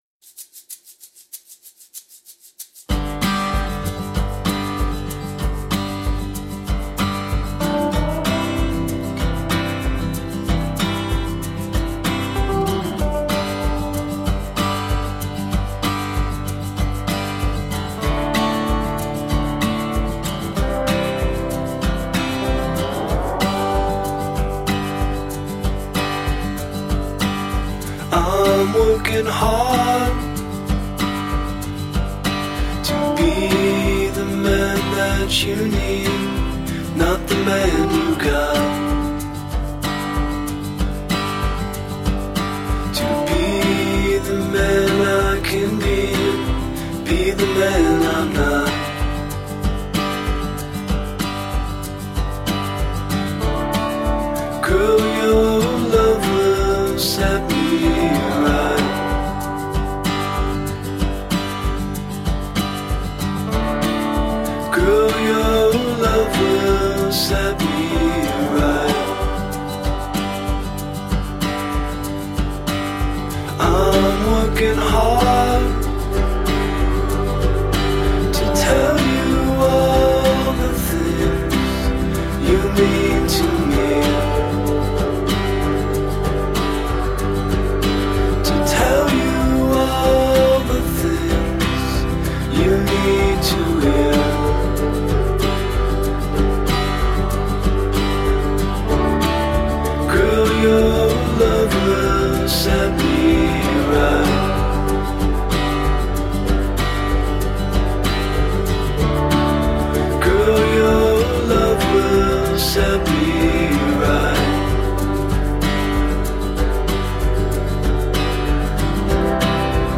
Melody-driven indie-folk.
folk-pop album
Tagged as: Alt Rock, Folk-Rock